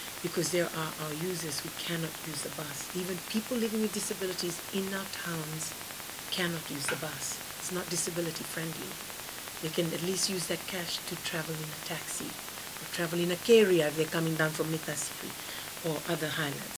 Minister for Social Protection, Lynda Tabuya